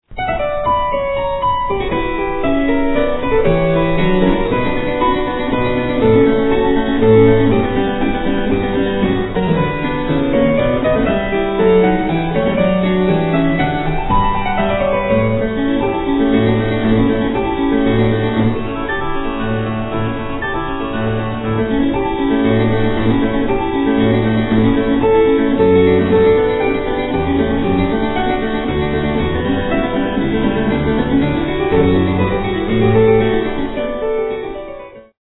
harpsichordist
Sonata for keyboard in B minor, K. 27 (L. 449) - 3:41